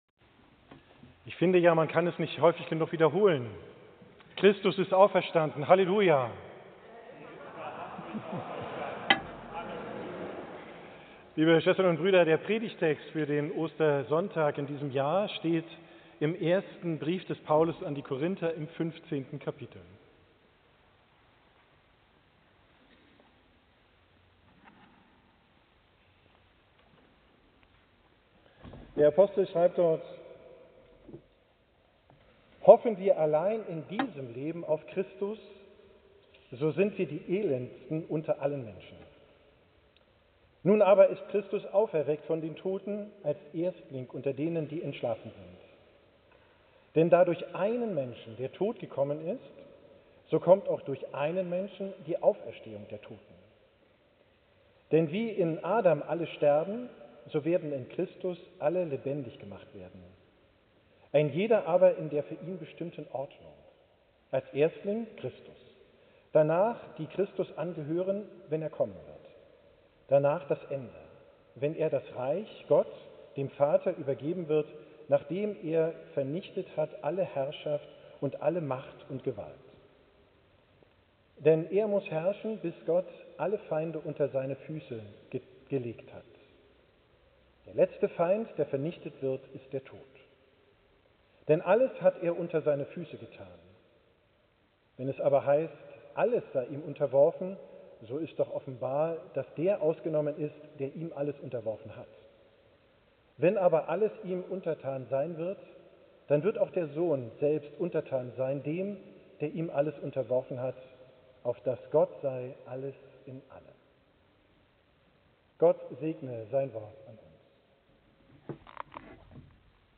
Predigt vom Ostersonntag, 5.